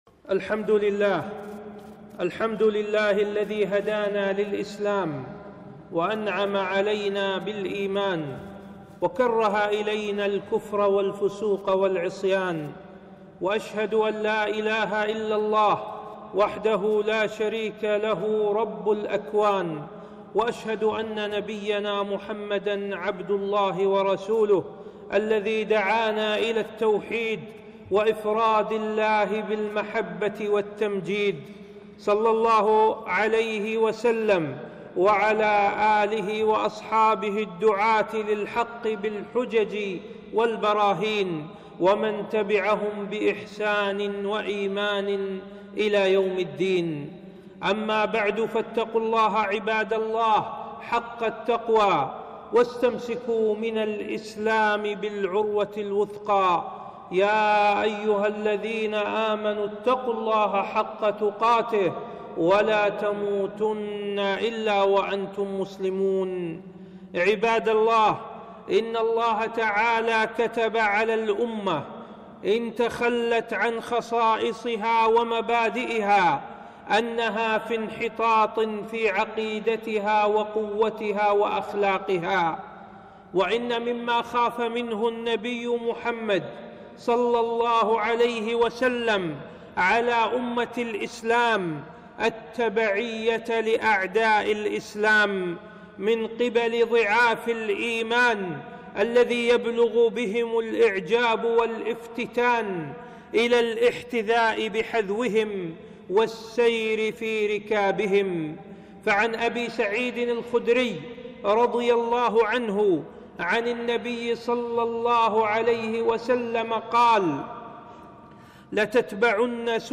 خطبة - حكم الكرسمس ورأس السنة وشجرة الميلاد